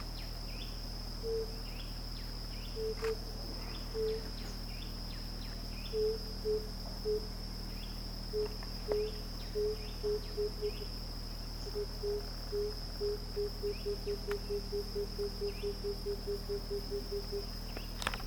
Palomita Tamborilera (Turtur tympanistria)
Nombre en inglés: Tambourine Dove
Localidad o área protegida: Bwindi National Park
Condición: Silvestre
Certeza: Fotografiada, Vocalización Grabada
Dove.mp3